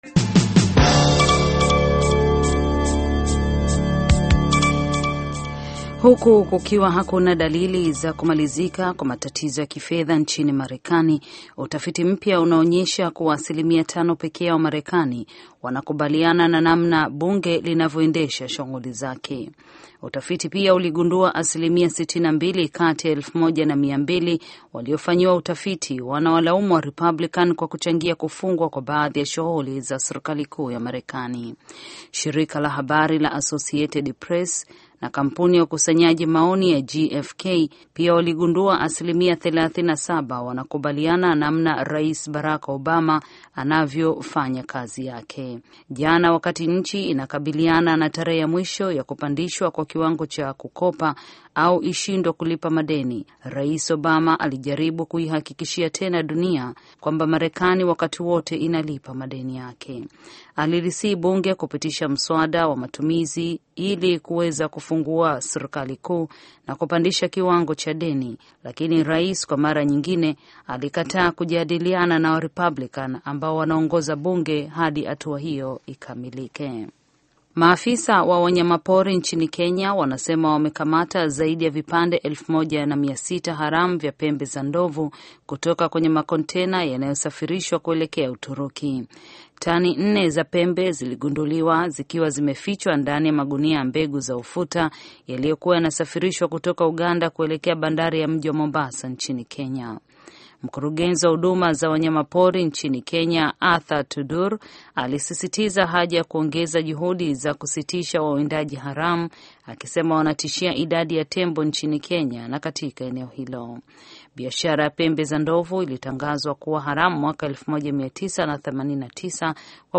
Taarifa ya Habari VOA Swahili - 6:35